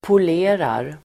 Uttal: [pol'e:rar]